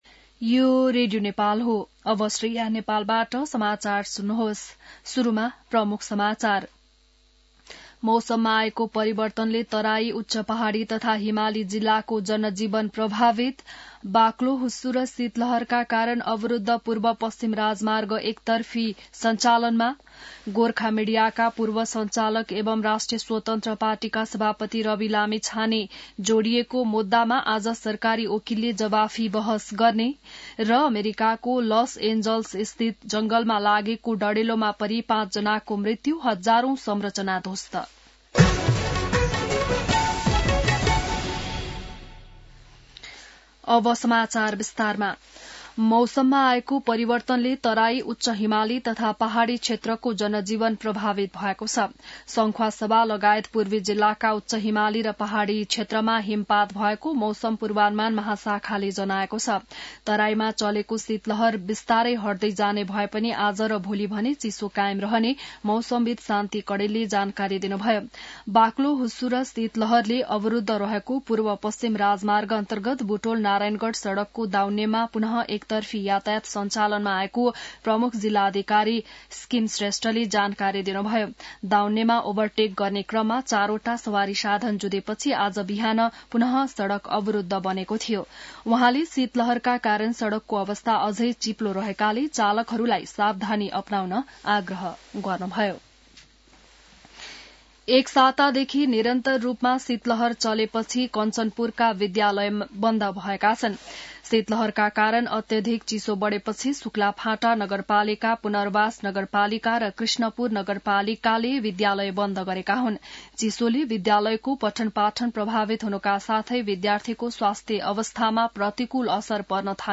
बिहान ९ बजेको नेपाली समाचार : २६ पुष , २०८१